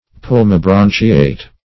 [NL.], Pulmobranchiate \Pul`mo*bran"chi*ate\, a. & n.(Zool.)
pulmobranchiate.mp3